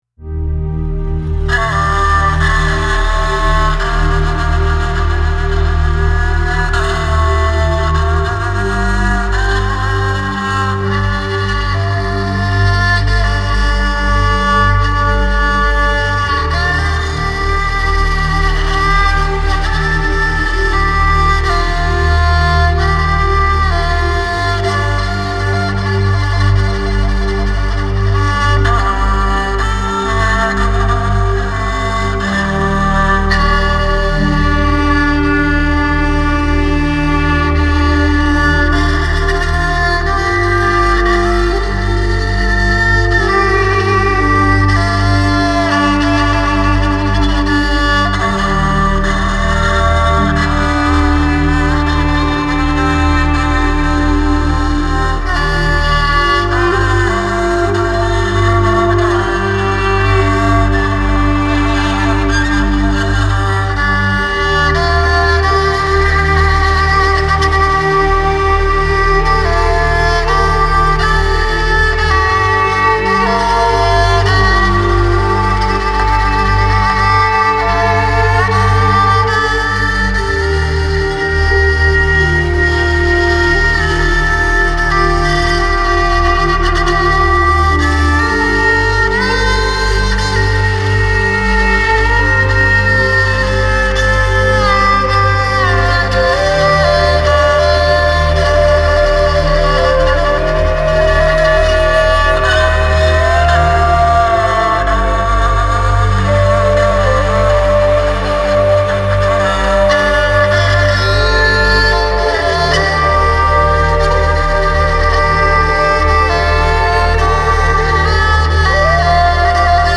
เพลงไทยเดิม
ของเว็บไทยรัฐน่ะครับ ฟังแล้วเศร้ามาก